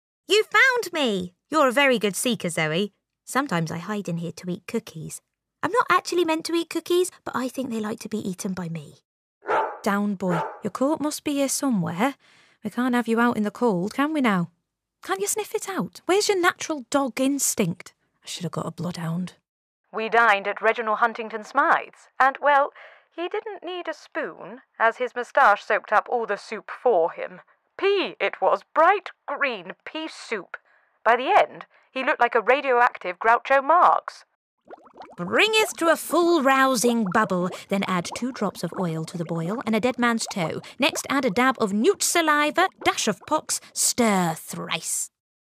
Animation Reel
• Native Accent: RP
a keen ear for accents and an adaptable character voice for games and animation.